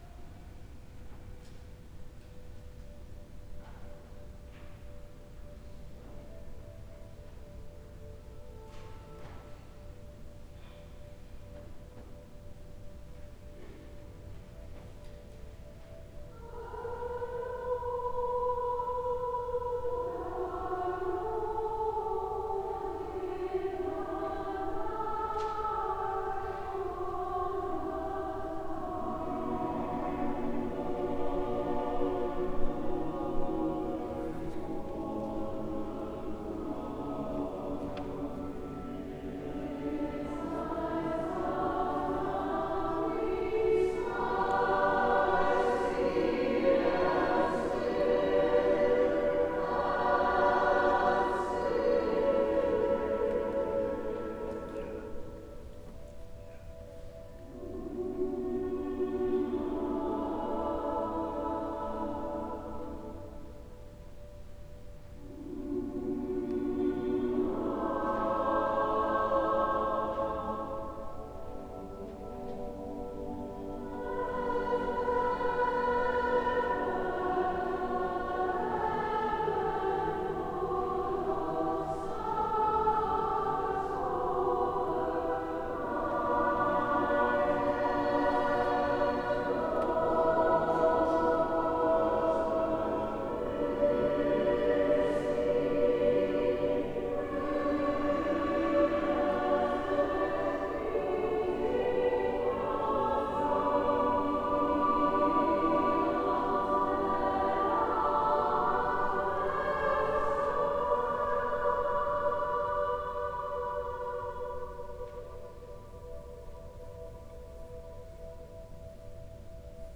Exerpts from Come Sing Christmas
Ottawa, December 2024